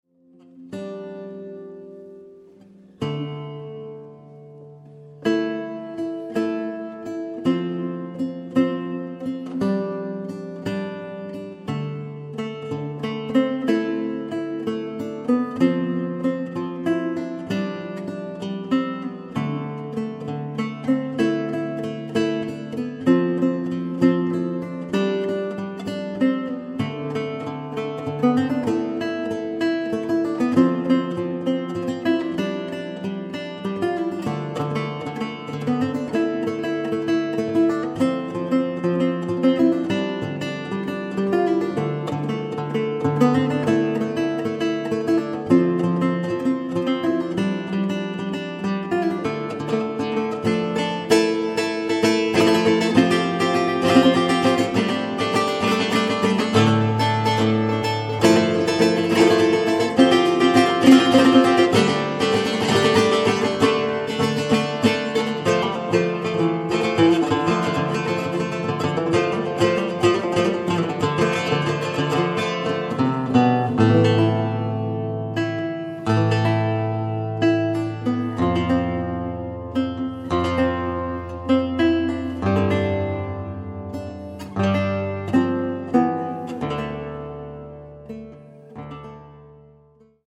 Contemporary
Lute , Vivid